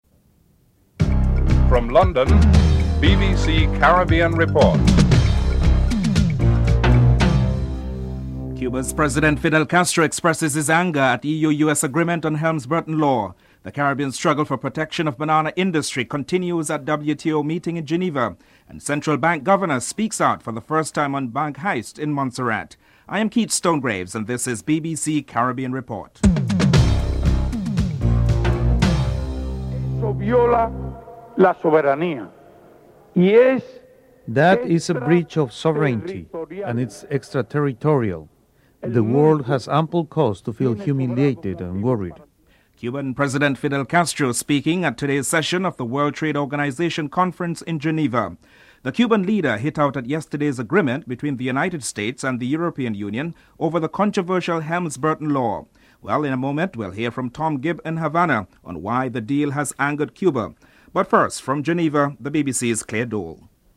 3. Another trade deal up for discussion at the WTO conference is that of Caribbean bananas. Dominica's Prime Minister Edison James comments on what he expected from the meeting (04:30-06:25)
4. The Governor of the Eastern Caribbean Central Bank (ECCB), Dwight Venner spoke publicly for the first time about the recent bank heist at the Barclays Bank in the abandoned Montserrat capital of Plymouth (06:26-09:24)